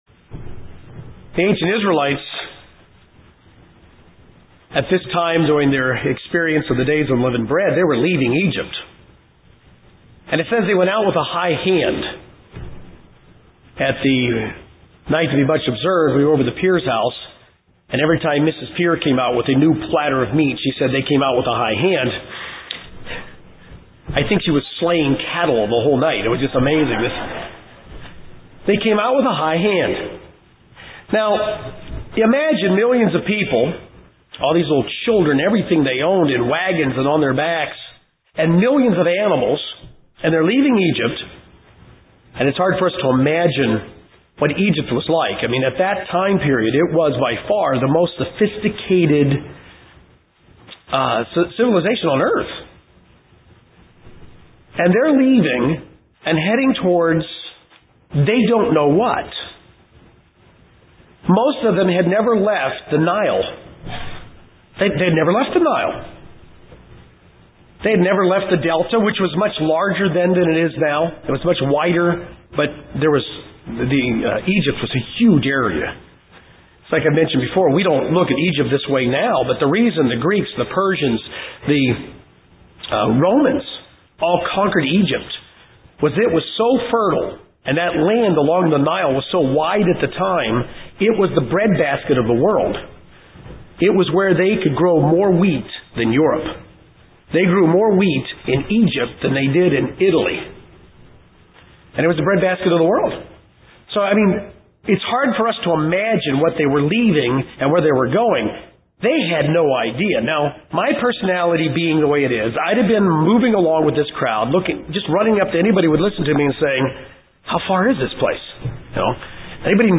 This sermon will look at some Old Testament scriptures about the Israelites experience then see how Paul uses these experiences to teach the church and how we can learn from these things.